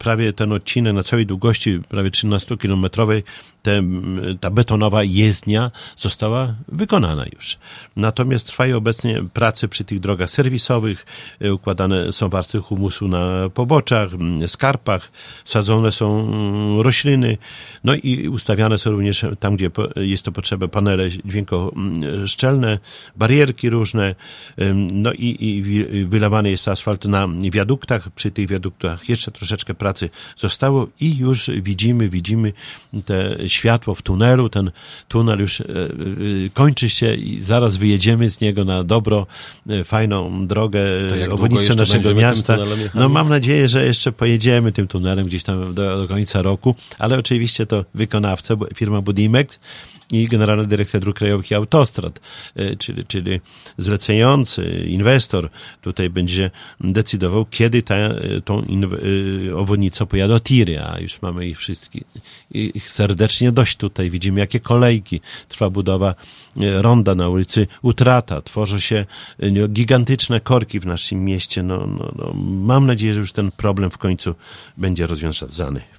O szczegółach mówił w piątek (28.09) na antenie Radia 5 Czesław Renkiewicz, prezydent Suwałk.